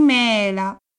[mɛla]